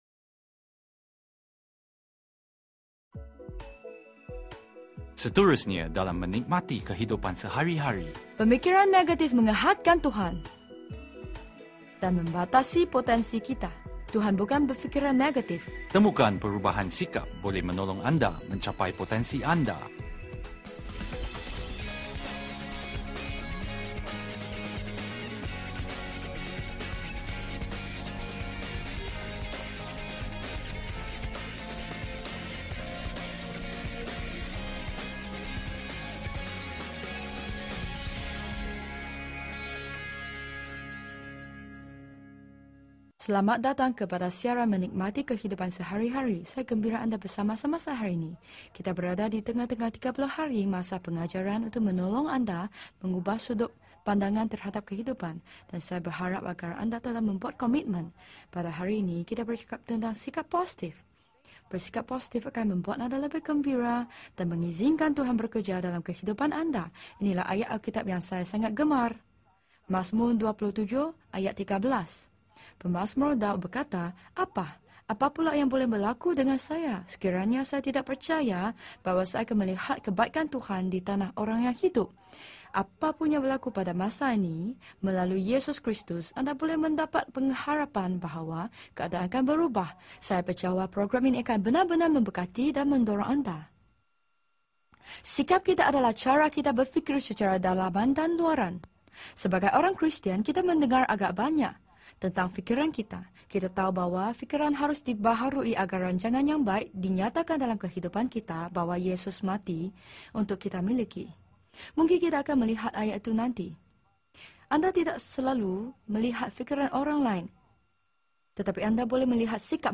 Dengarkan bagaimana pengarang dengan penjualan terbaik dan guru Alkitab, Joyce Meyer, dalam membagikan dorongan dan semangat untuk membantu anda dalam menikmati kehidupan sehari-hari